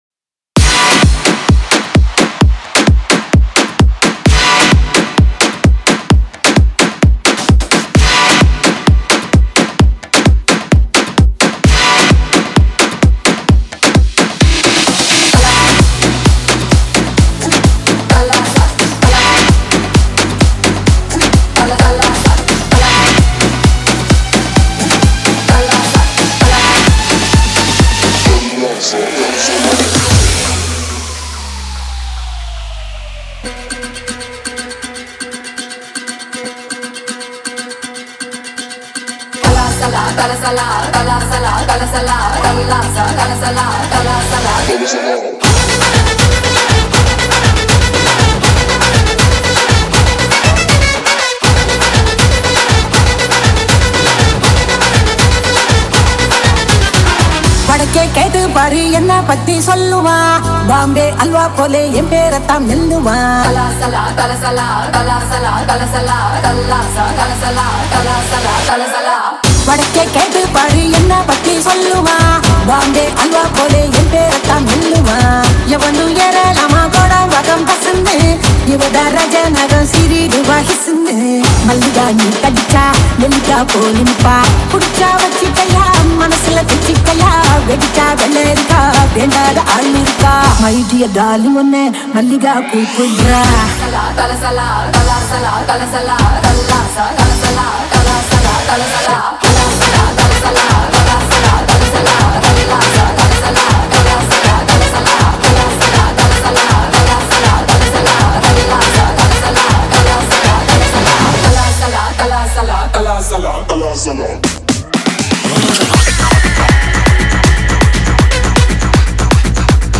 Single DJ Remix Songs